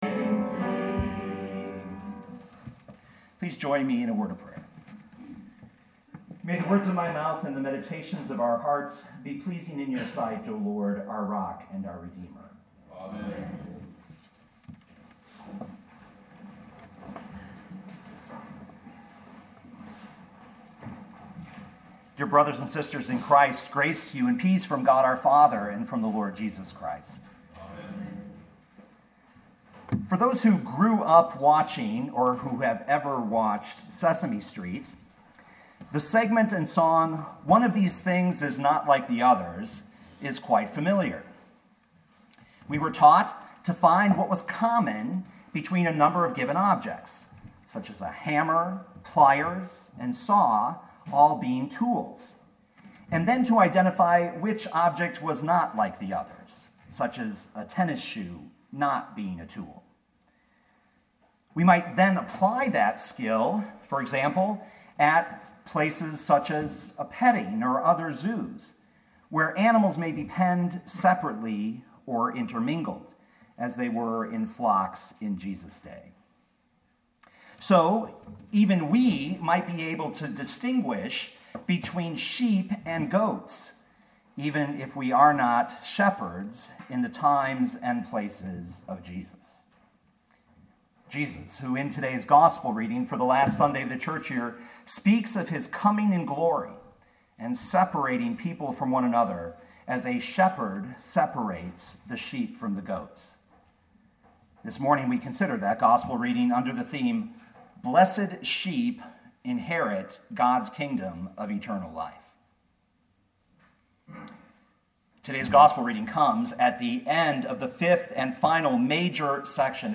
2017 Matthew 25:31-46 Listen to the sermon with the player below, or, download the audio.